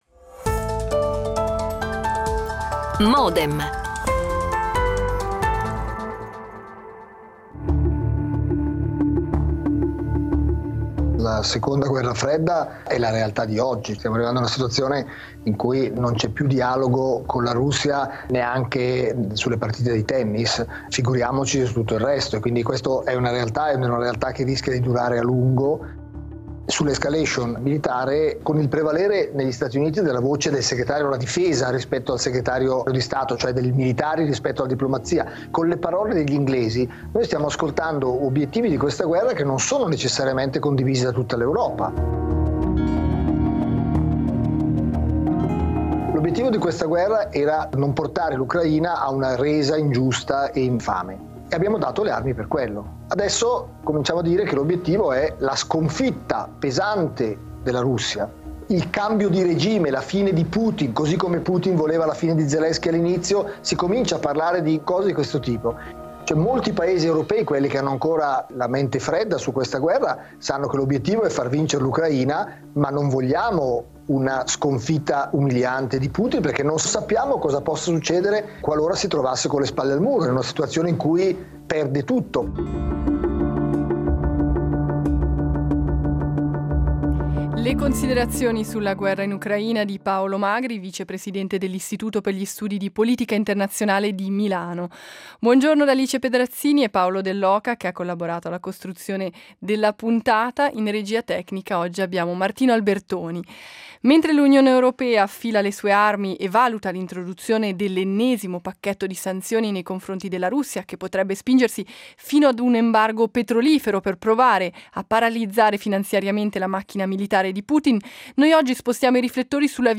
Intervista con la diplomatica Heidi Tagliavini
L'attualità approfondita, in diretta, tutte le mattine, da lunedì a venerdì